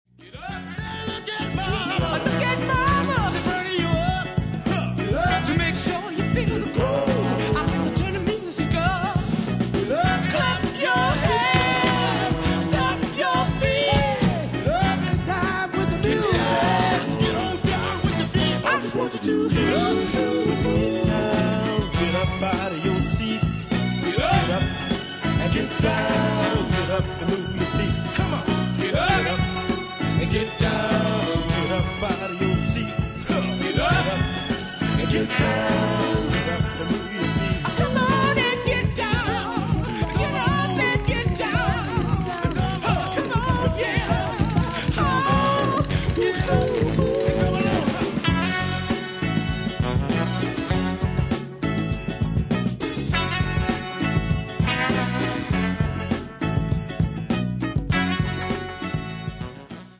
funkier soul